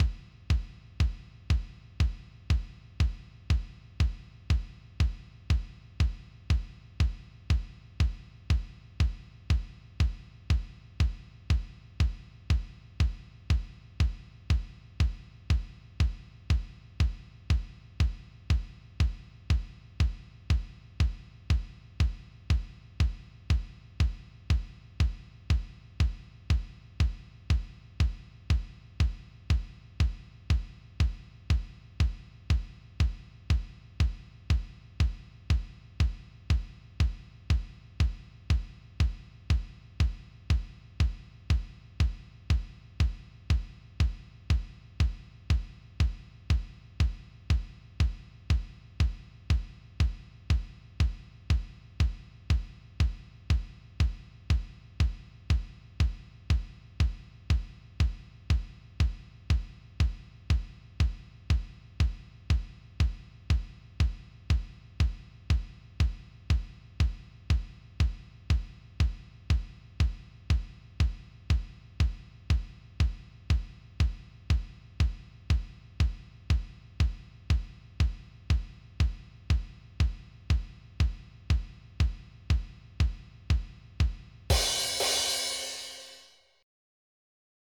MIDI Music File
BASSDRUM.mp3